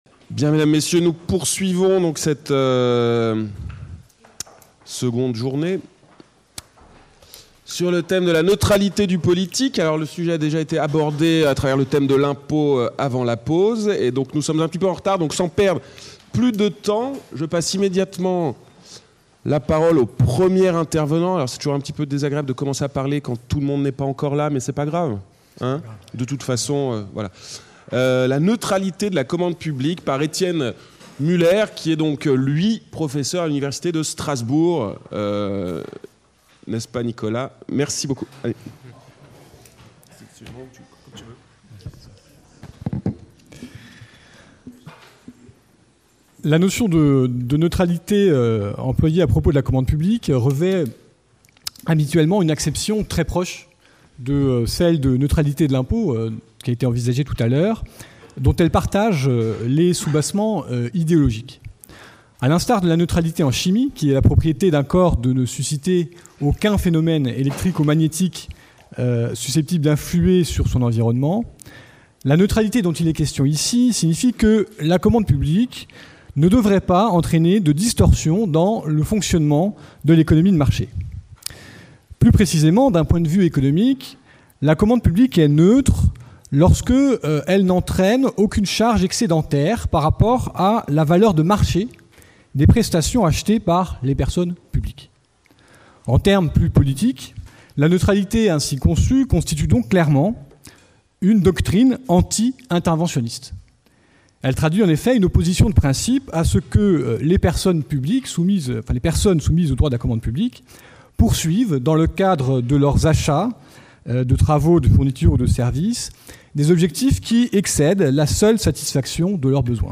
Dialogue : La neutralité de l’impôt
Retour sur le colloque : La neutralité (13 et 14 octobre 2016) Colloque de la promotion 2014 de l'agrégation de droit public Le colloque des 13 et 14 octobre 2016, réunissant les lauréats et membres du jury du concours d’agrégation de droit public 2014, a été l’occasion de mettre en perspective avec succès la neutralité en droit, en interrogeant ses raisons d’être et ses formes, mais en insistant aussi sur ses limites voire ses impasses.